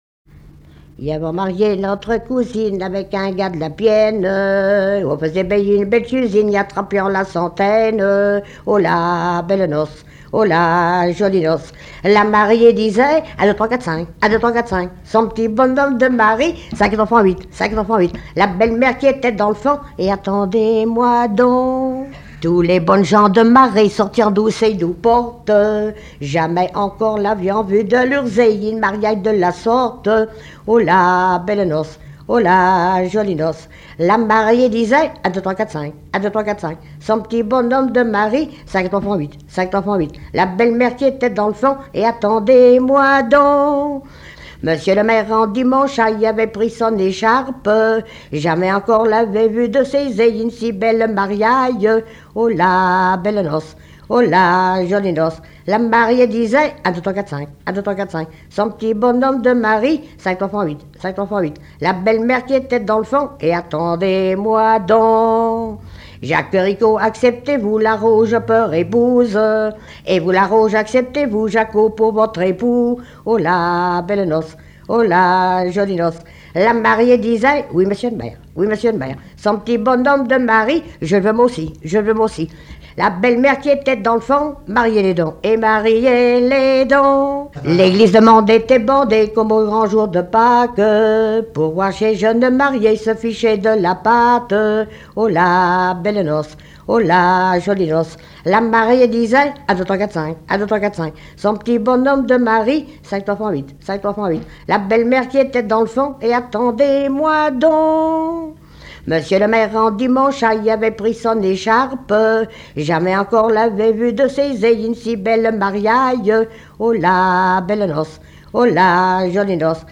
Genre strophique
Chansons, témoignages et instrumentaux
Pièce musicale inédite